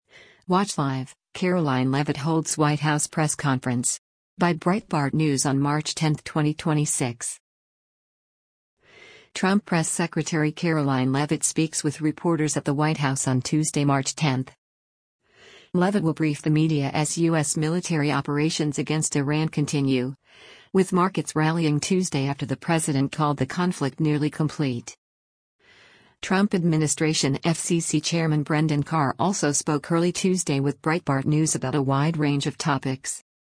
Trump Press Secretary Karoline Leavitt speaks with reporters at the White House on Tuesday, March 10.